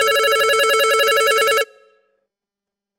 Phone Ringing
A classic telephone ringing with a bright, metallic bell tone in a quiet room
phone-ringing.mp3